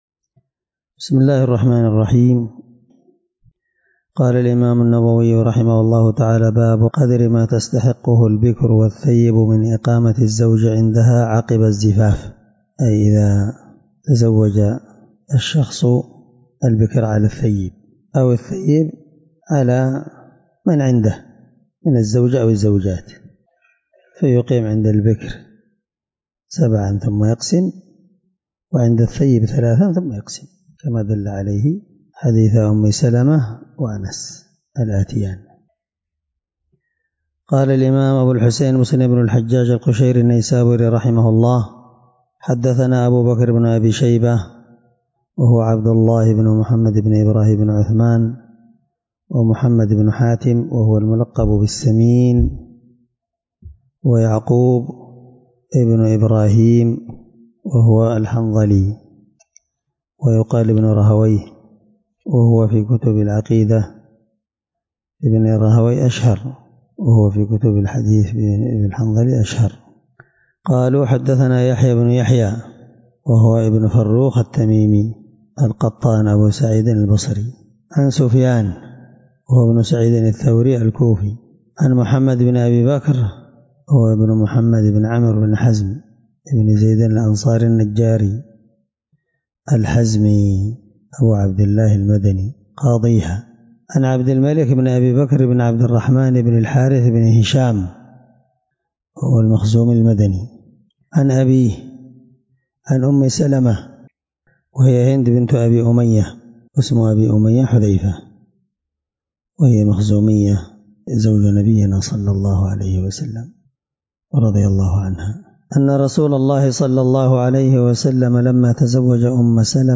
الدرس12 من شرح كتاب الرضاع حديث رقم(1460-1461) من صحيح مسلم